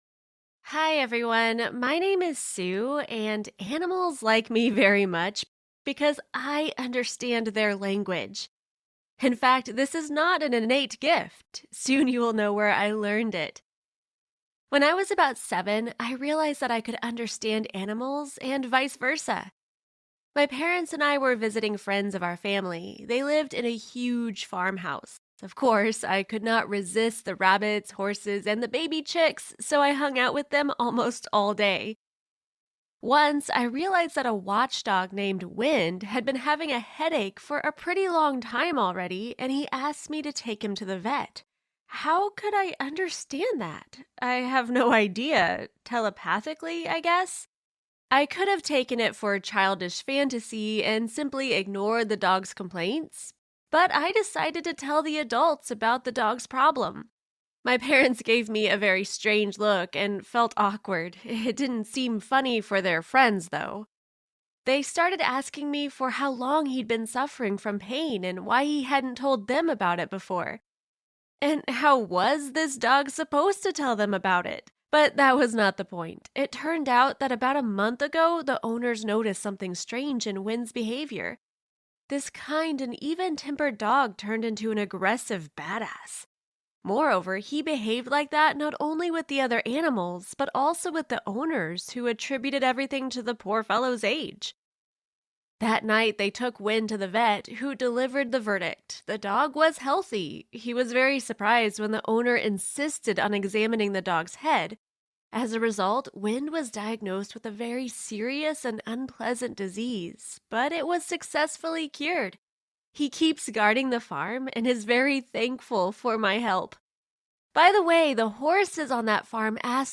Join us as we follow the incredible story of a protagonist who discovers their innate ability to communicate with animals, unraveling the mysteries of interspecies connection. Through vivid storytelling and immersive sound design, we transport you to a realm where humans and animals forge a unique bond, blurring the boundaries of language and understanding.